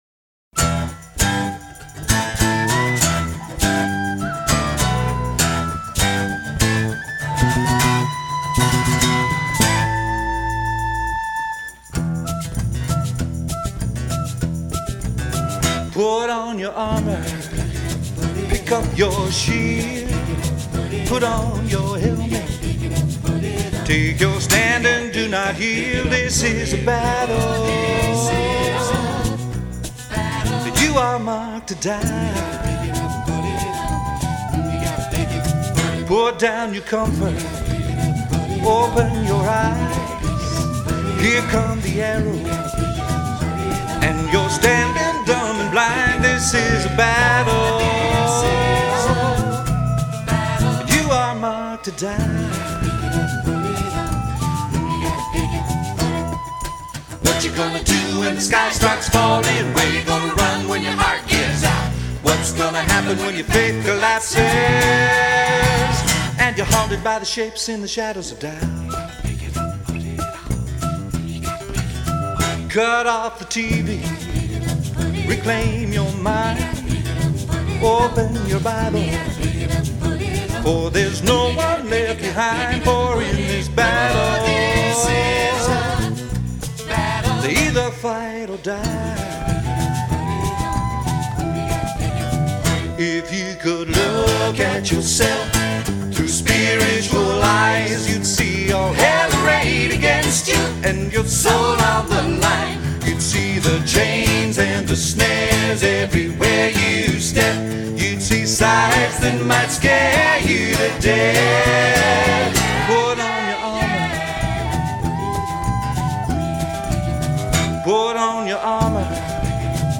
free christian music download
on lead guitar